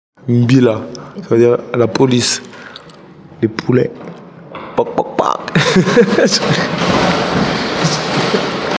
mbila_explication.mp3